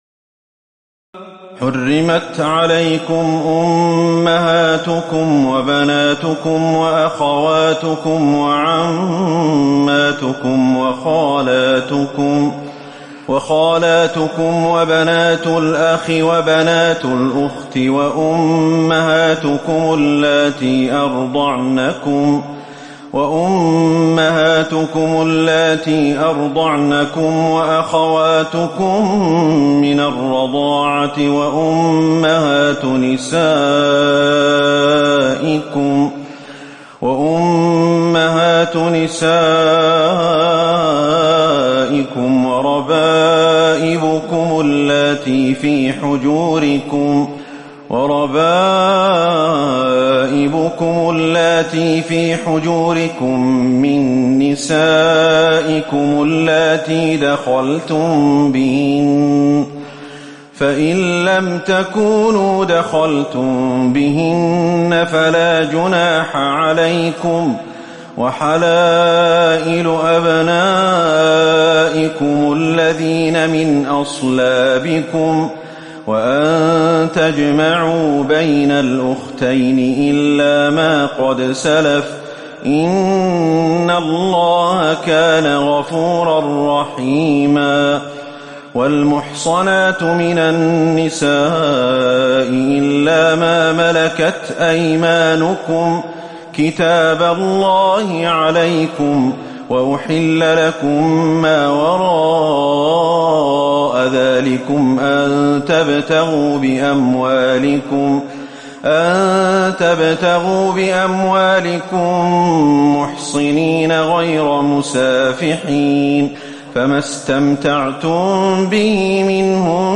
تراويح الليلة الخامسة رمضان 1438هـ من سورة النساء (23-87) Taraweeh 5 st night Ramadan 1438H from Surah An-Nisaa > تراويح الحرم النبوي عام 1438 🕌 > التراويح - تلاوات الحرمين